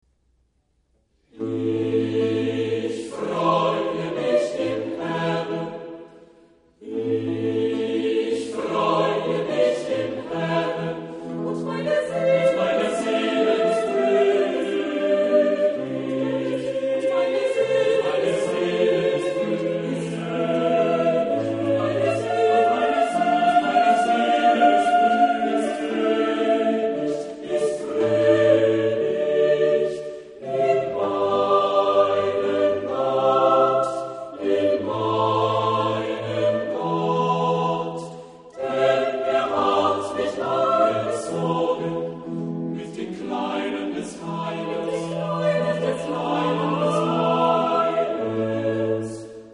Epoque: 17th century  (1600-1649)
Genre-Style-Form: Sacred
Type of Choir: SSATB  (5 mixed voices )
Instrumentation: Continuo
sung by Dresdner Kammerchor conducted by Hans-Christoph Rademann